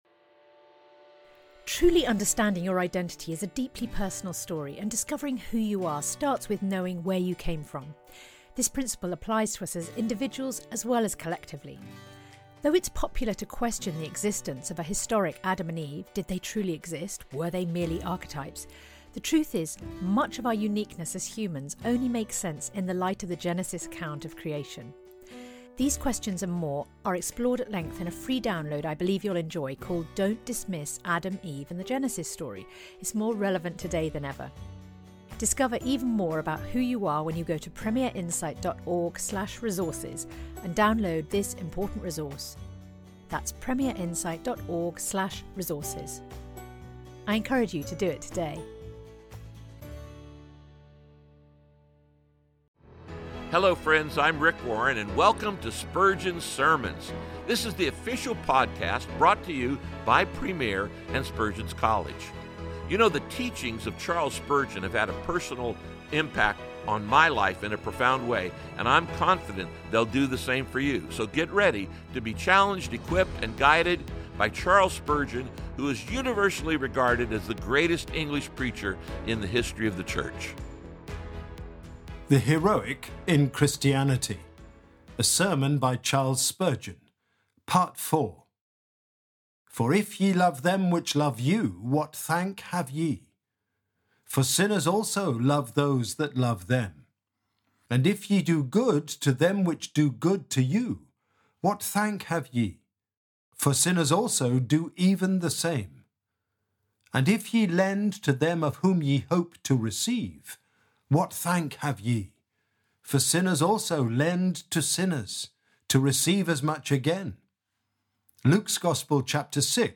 Part 4 of a sermon by Charles Spurgeon from February 20, 1881.